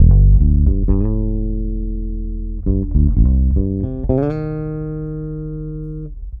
Bass 29.wav